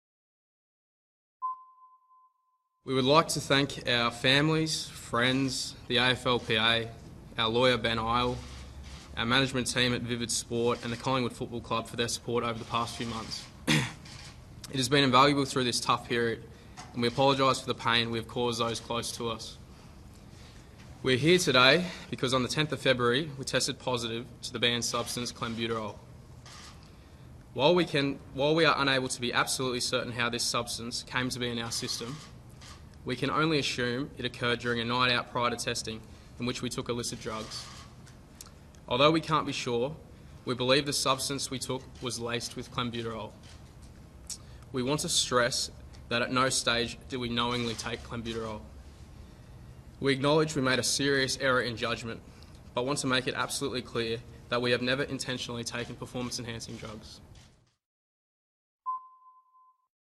Listen to Josh Thomas read a statement to the media following the news of his two-year suspension on Monday 10 August 2015.